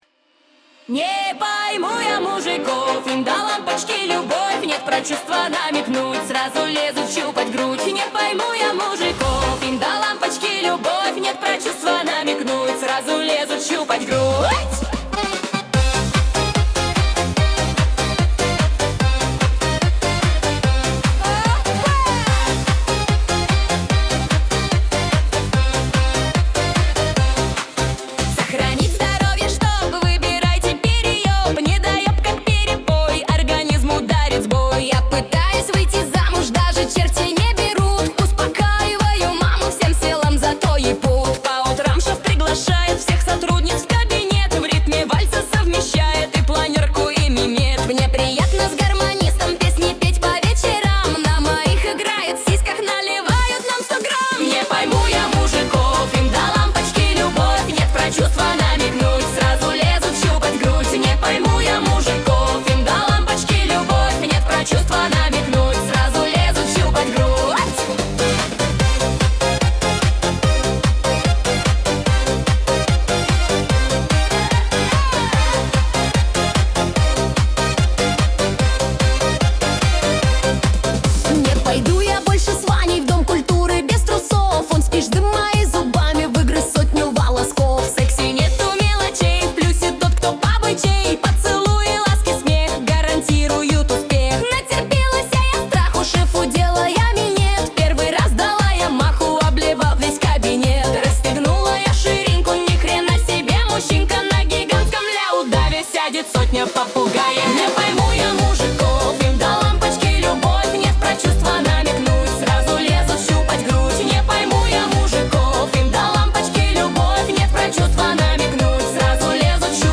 Качество: 237 kbps, stereo